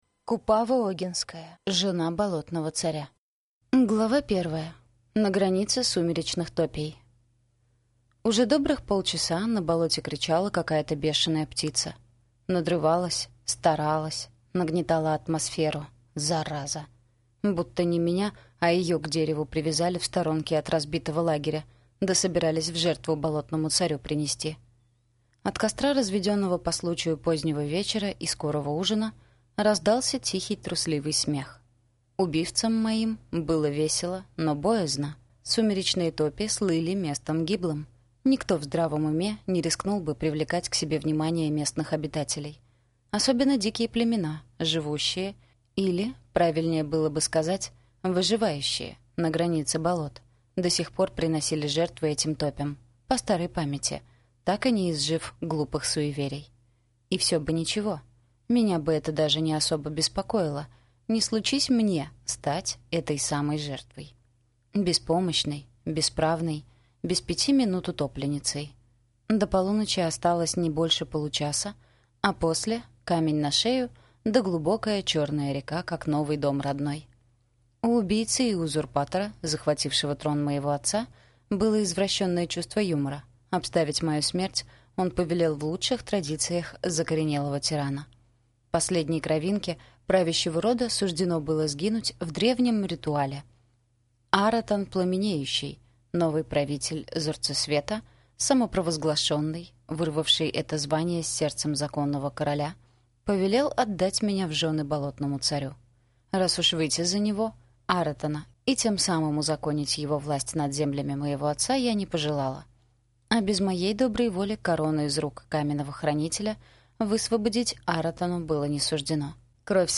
Аудиокнига Жена болотного царя | Библиотека аудиокниг